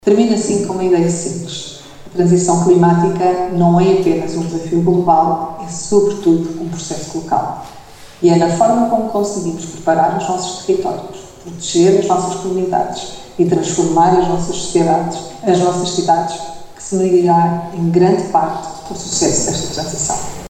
Acompanhando esta visão, Ana Teresa Pérez, Presidente da Agência para o Clima, reforçou a ideia de que o sucesso das metas nacionais e europeias depende inteiramente da capacidade de execução ao nível municipal.